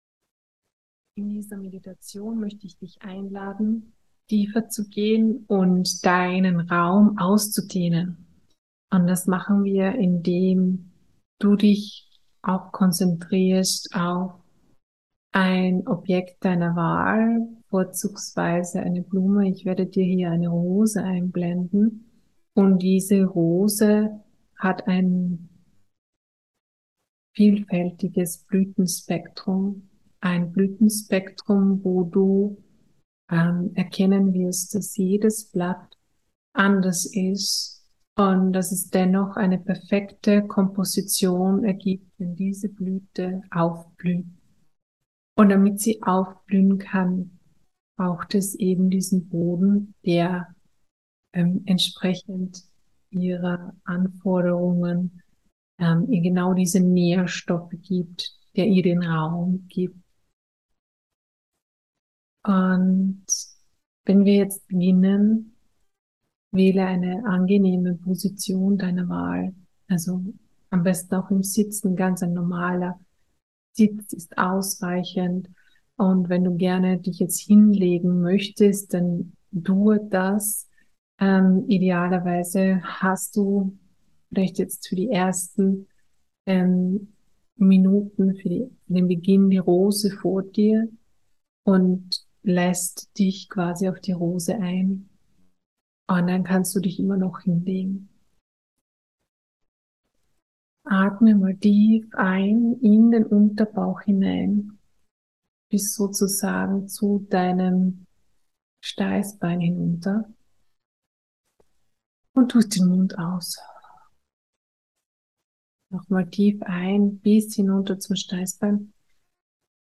Meditation-Rose.mp3